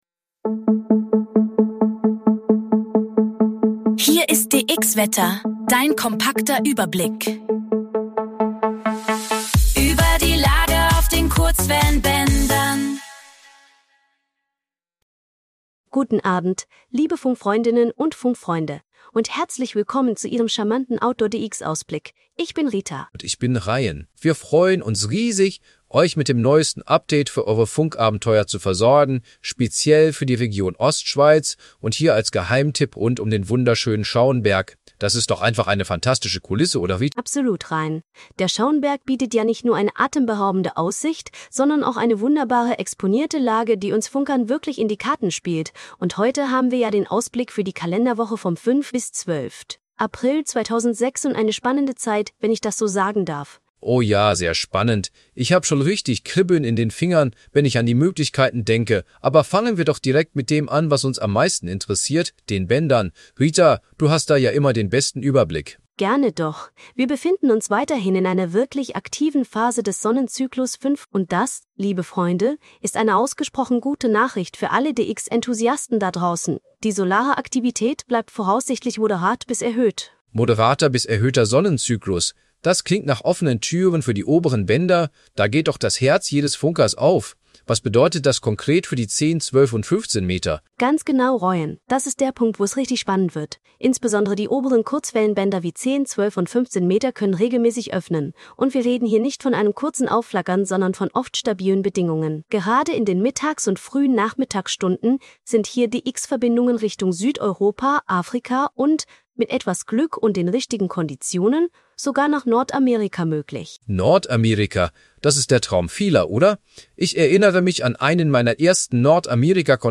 KI-generierter Inhalt - aktuell für die Region Ostschweiz